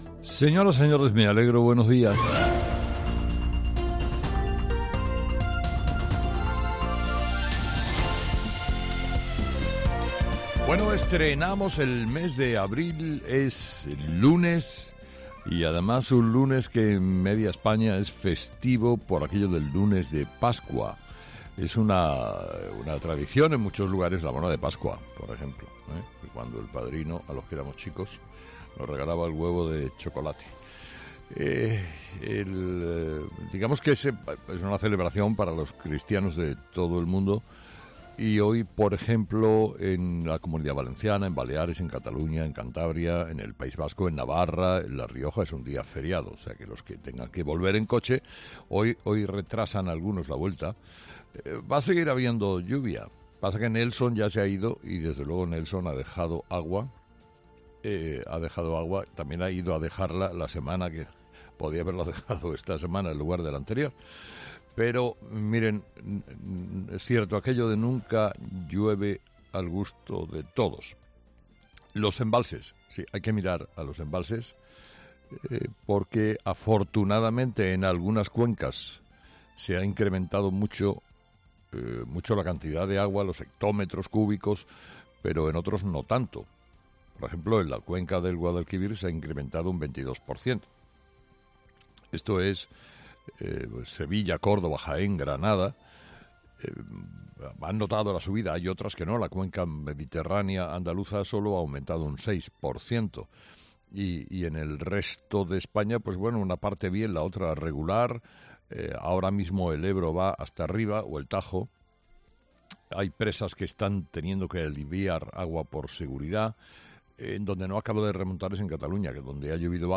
Carlos Herrera, director y presentador de 'Herrera en COPE', comienza el programa de este lunes analizando las principales claves de la jornada que pasan, entre otras cosas, por el Senado analiza el caso Koldo y la trama de las mascarillas.